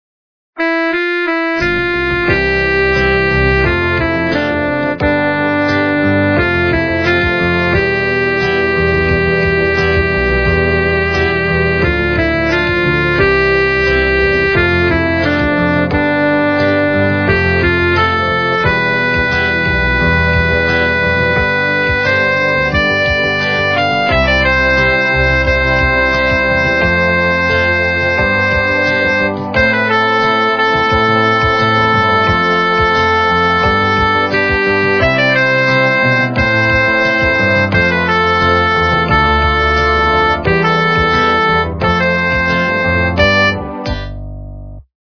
полифоническую мелодию